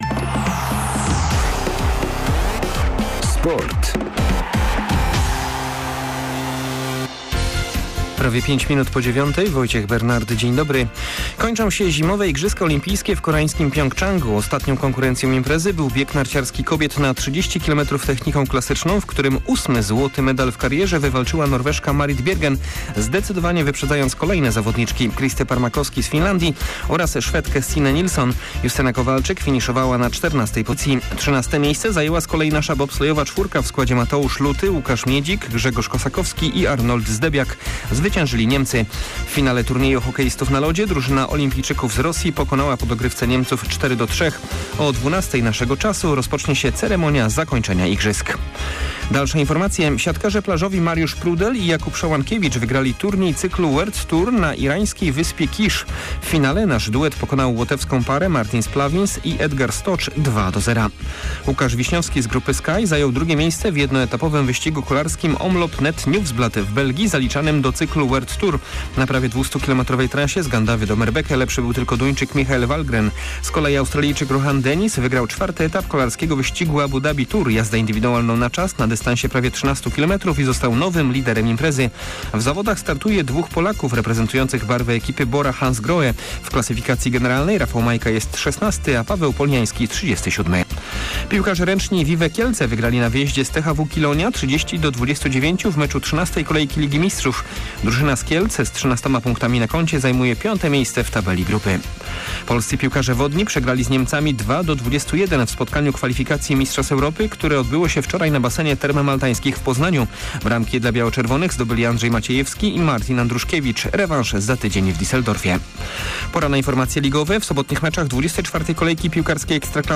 25.02 serwis sportowy godz. 9:05
W niedzielnym serwisie zapraszamy na podsumowanie ostatniego dnia Zimowych Igrzysk Olimpijskich w koreańskim Pjongczangu. Nie zabraknie także wyników z boisk piłkarskiej ekstraklasy oraz zapowiedzi meczu Lecha Poznań z Koroną Kielce. Posłuchamy jak trener Kolejorza Nenad Bjelica ocenia najbliższego rywala.